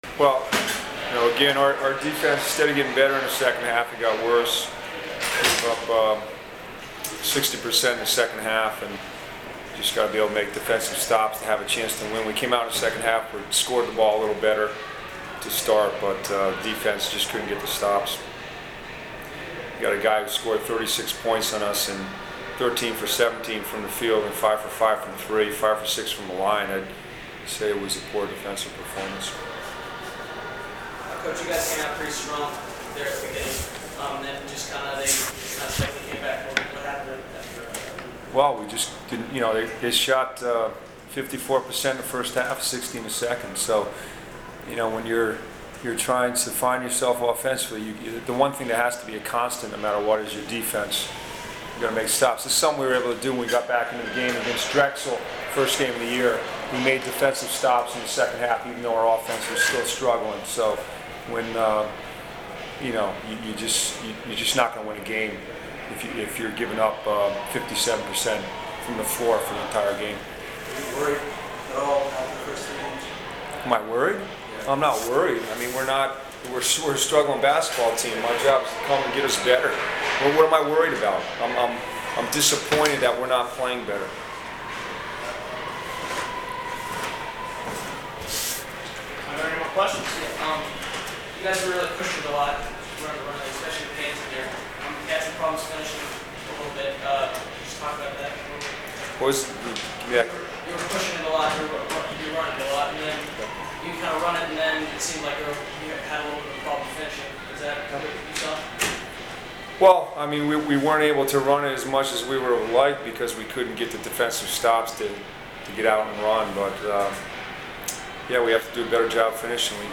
Postgame audio: